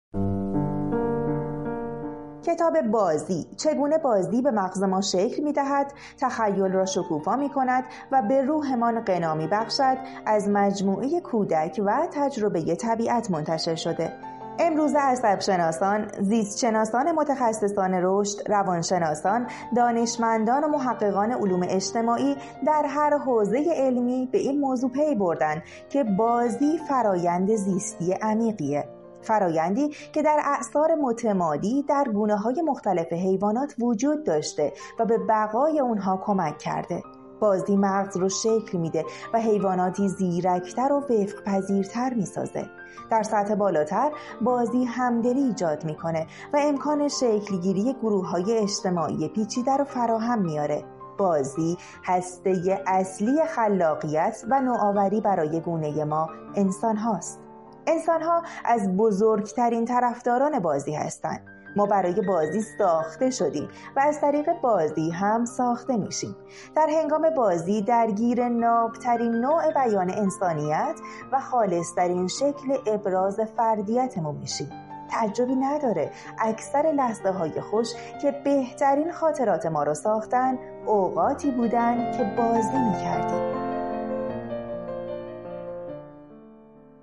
معرفی صوتی کتاب «بازی: چگونه بازی به مغز ما شکل می دهد، تخیل را شکوفا می کند و به روحمان غنا می بخشد» را بشنوید: